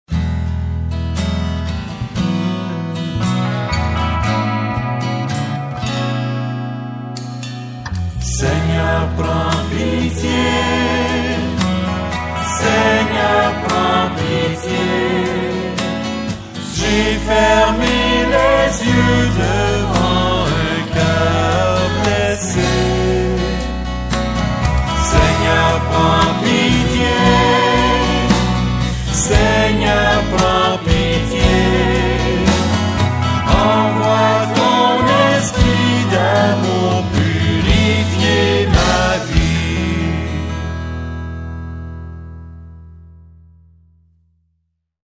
Chants divers
seigneur_prends_pitie_chant.mp3